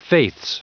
Prononciation du mot faiths en anglais (fichier audio)
Prononciation du mot : faiths